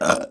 Hombre eructando 1
Sonidos: Acciones humanas Sonidos: Voz humana